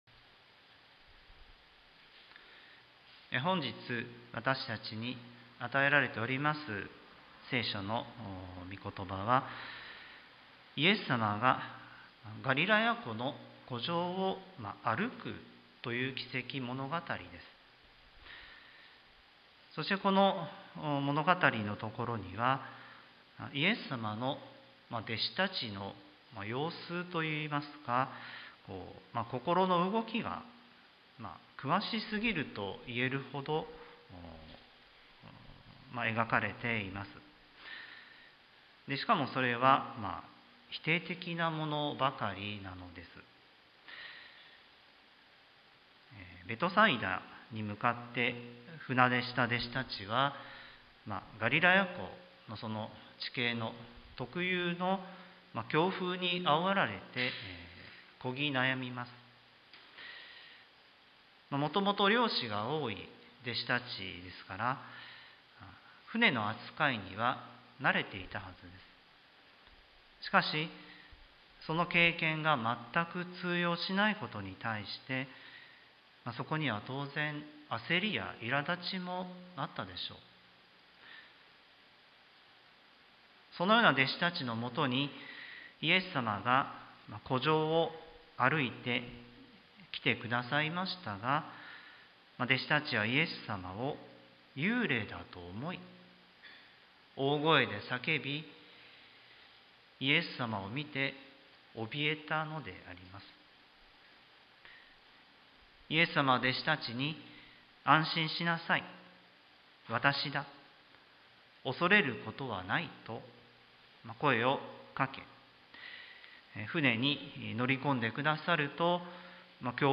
sermon-2021-08-22